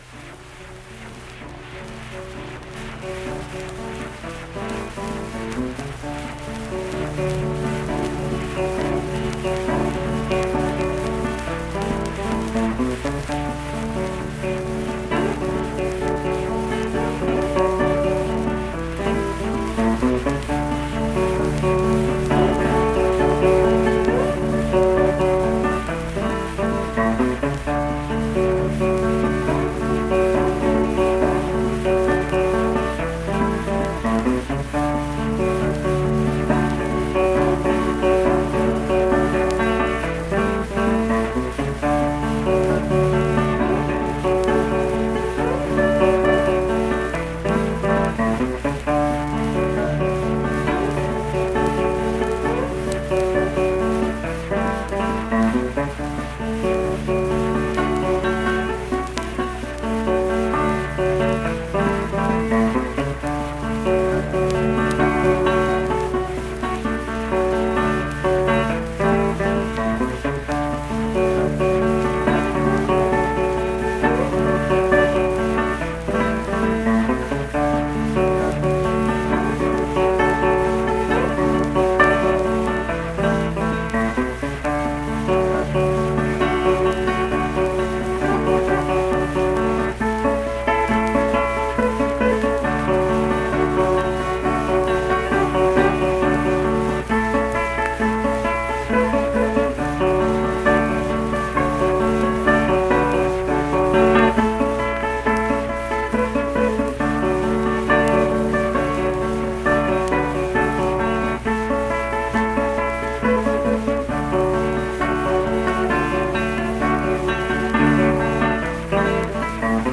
Unaccompanied guitar music
Verse-refrain 3(2). Performed in Hundred, Wetzel County, WV.
Instrumental Music
Guitar